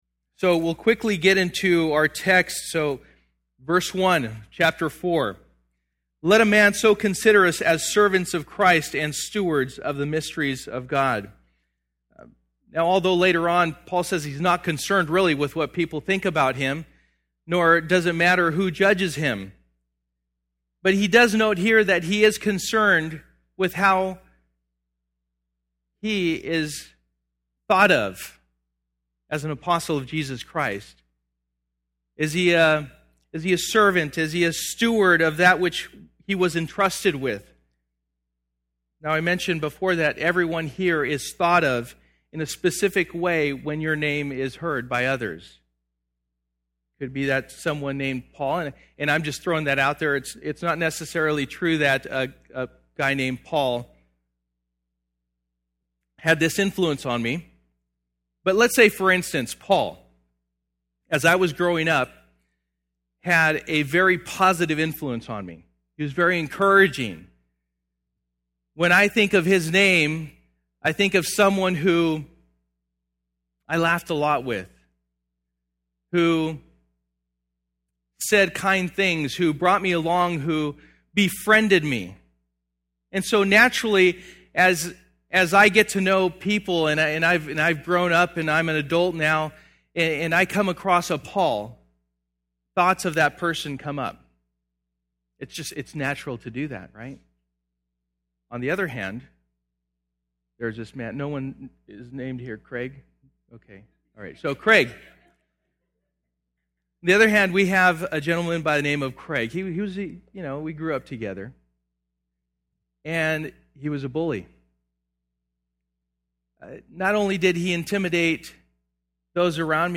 1 Corinthians 4:1-21 Service: Sunday Morning %todo_render% « Sold Out